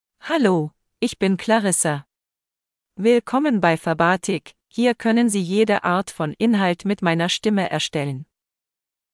FemaleGerman (Germany)
Voice sample
Female
German (Germany)